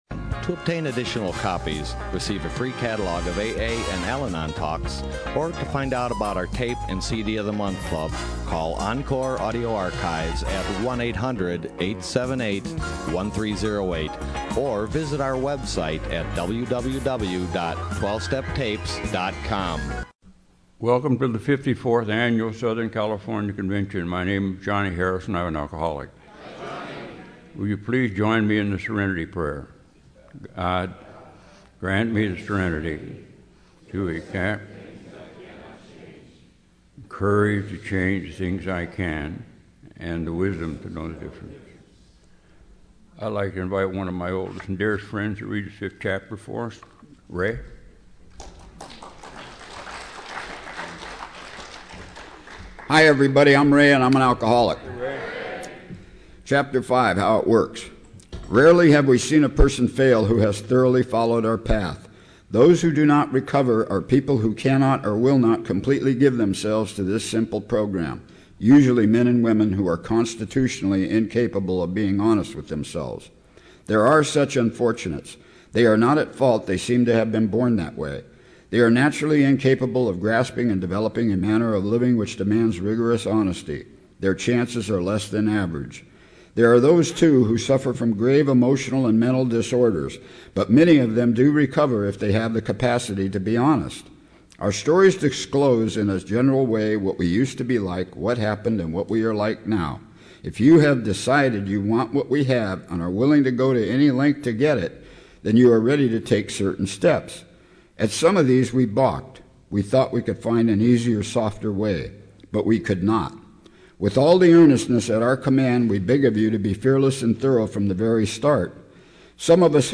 SoCAL AA Convention